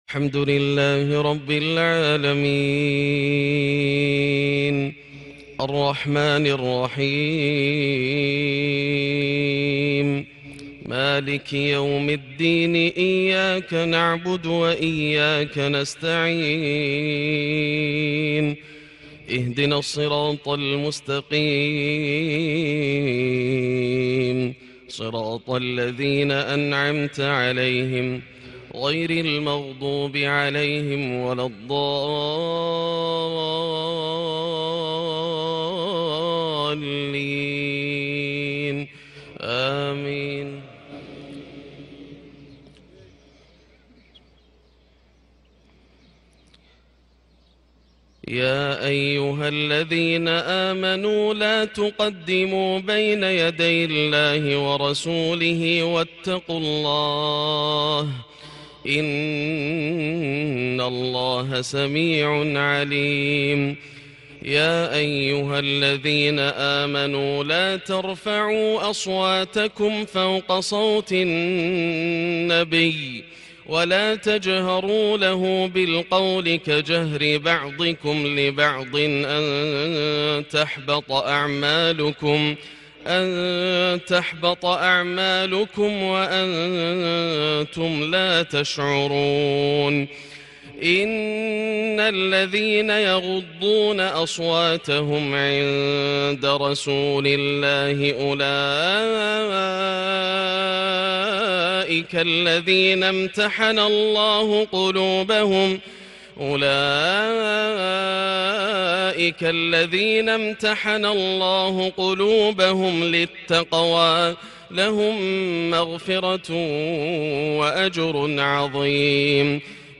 فجرية هادئة ومؤثرة لسورة الحجرات / فجر الاحد 9-5-1441 > عام 1441 > الفروض - تلاوات ياسر الدوسري